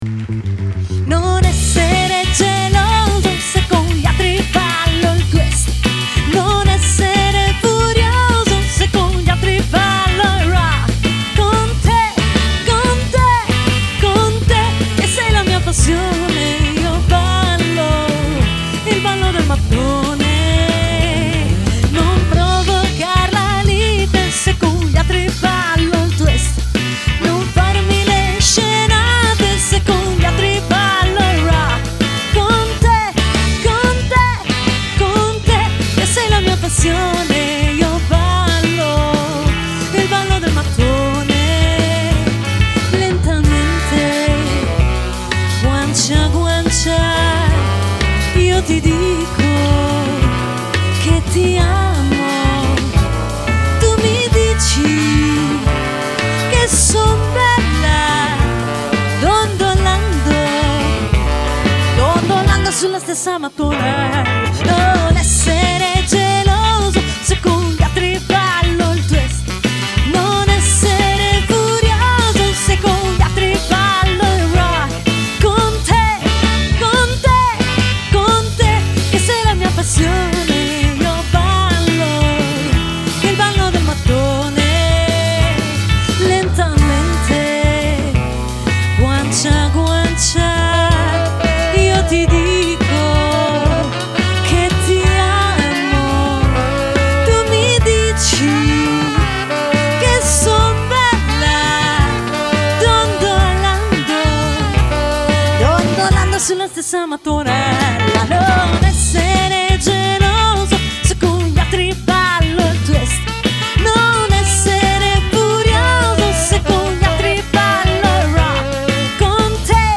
dal twist al beat a tanto altro ancora.
LIVE